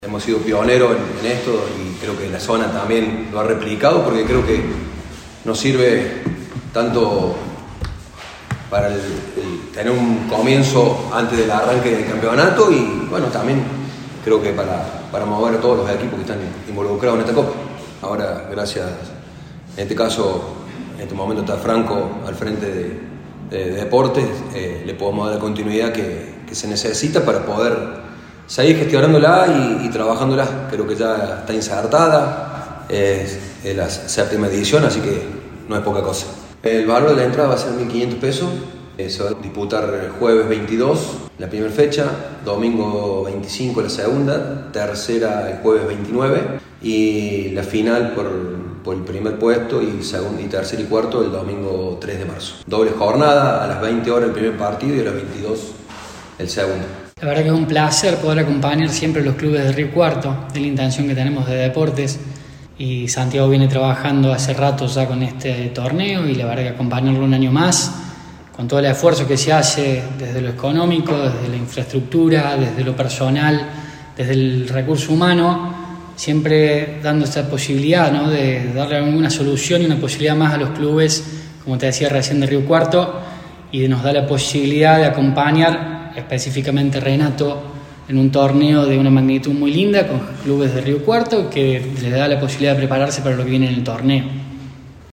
Durante la presnetación realizada en el Centro 11, las autoridades del club brindaron detalles sobre la competencia que se disputará antes del inicio de la liga de fútbol regional.